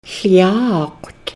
Listen to the elder